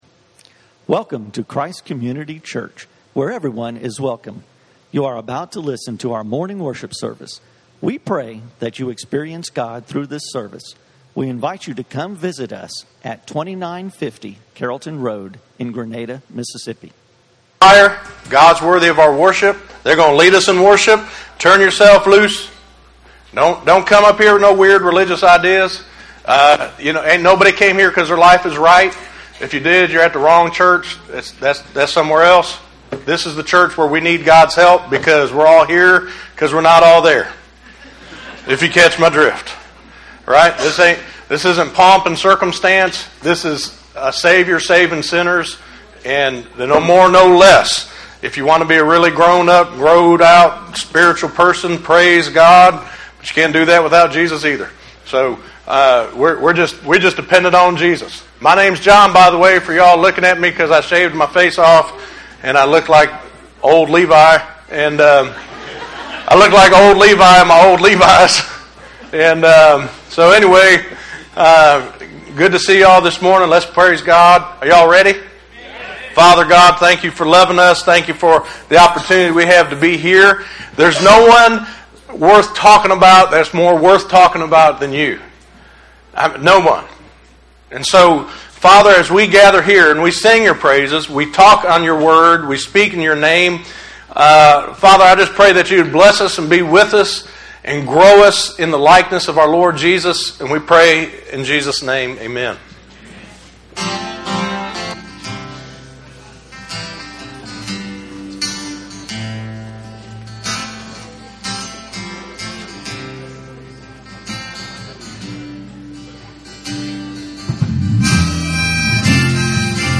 Real Sinners Need JESUS - Messages from Christ Community Church.